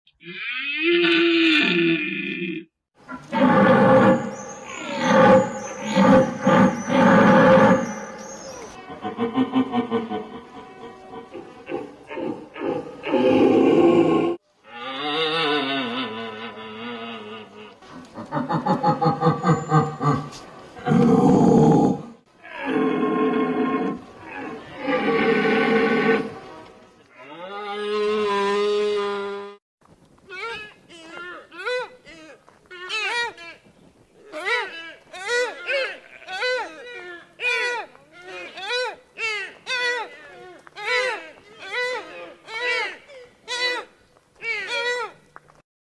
Deer Sounds Deer Live sound effects free download